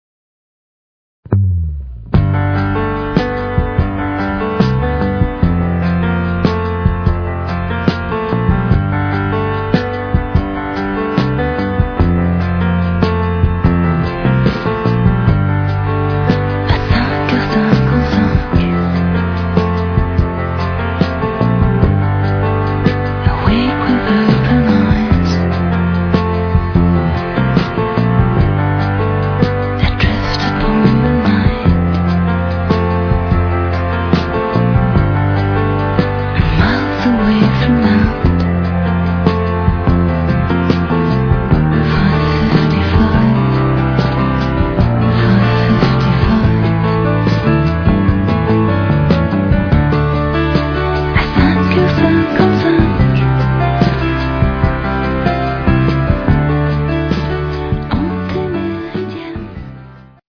Tilbagelænet fransk (mest på engelsk)